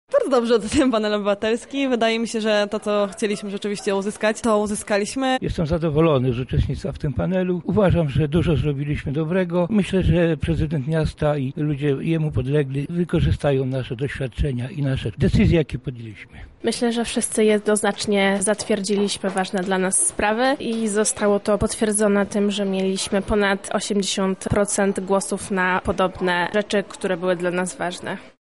W ciągu 6 spotkań toczyły się dyskusje nad rozwiązaniem problemu zanieczyszczenia powietrza w naszym mieście. 60 mieszkańcom, wylosowanym z każdej dzielnicy Lublina, po wielogodzinnych debatach udało się dojść do porozumienia i wspólnych wniosków.